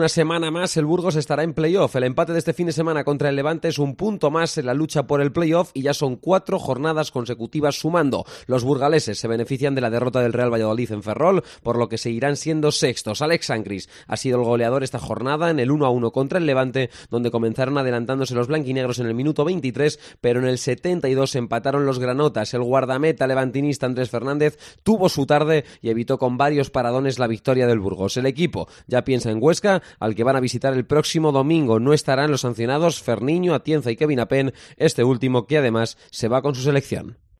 Crónica del Burgos CF 1-1 Levante